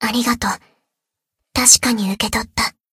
BA_V_Shiroko_Ridingsuit_Battle_Recovery_1.ogg